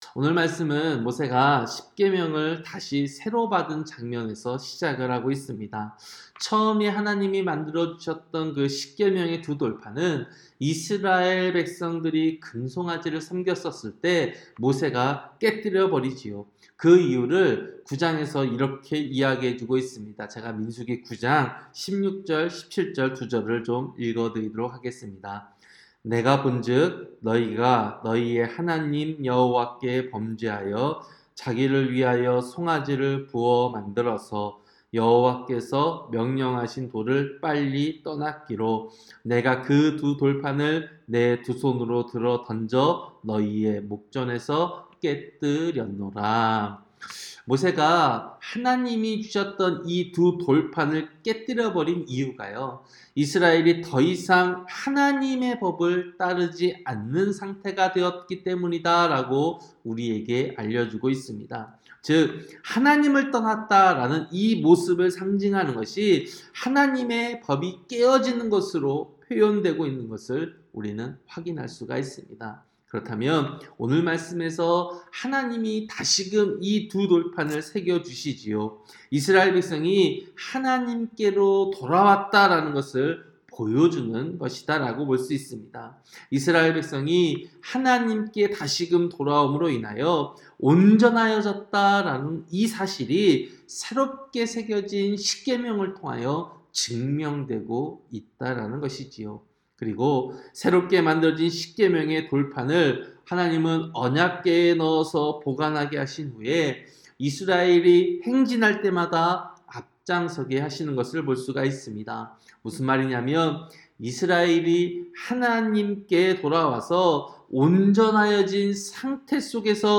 새벽설교-신명기 10장